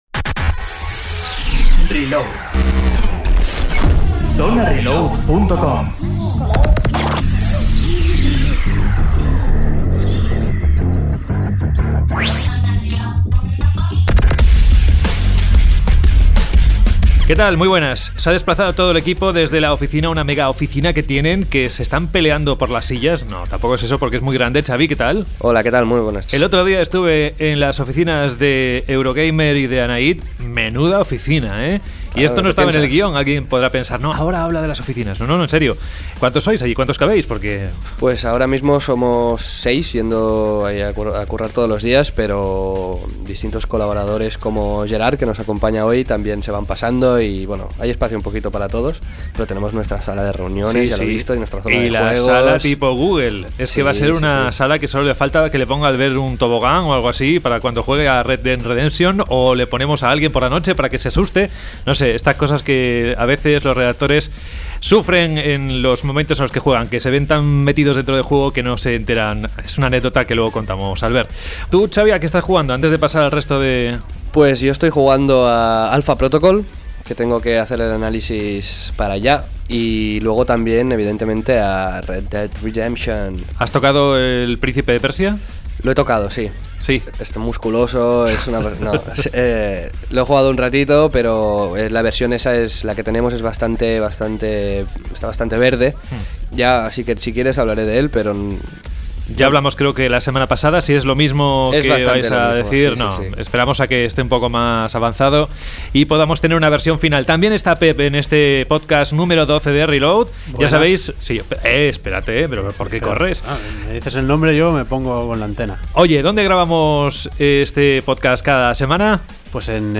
Reload se graba en los estudios de Scanner FM , una radio alternativa que seguro que os gustará y que podéis escuchar por Internet.